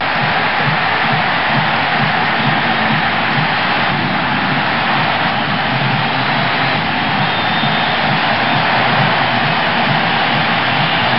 crowds.wav